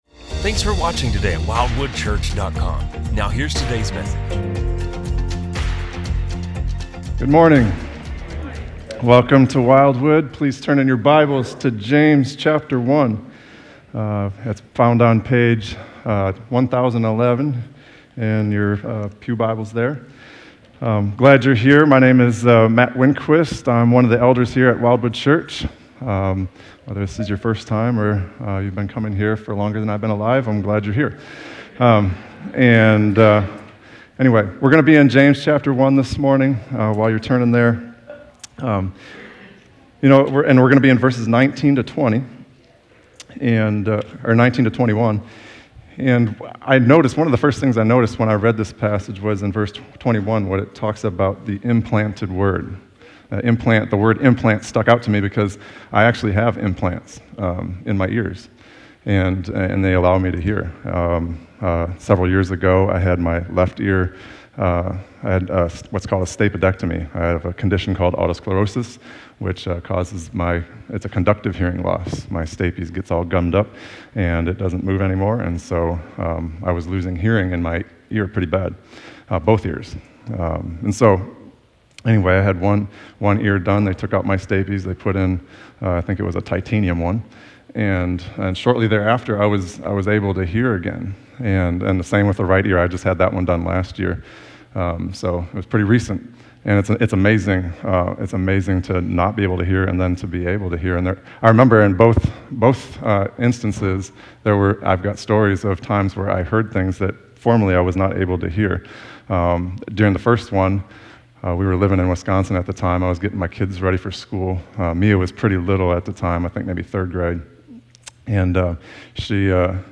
A message from the series "Wisdom From Above." We are not in control of our destiny as the world would have us believe. Rather God is sovereign over all aspects of our lives.